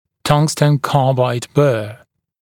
[‘tʌŋstən ‘kɑːbaɪd bɜː][‘танстэн ‘ка:байд бё:]бор из карбида вольфрама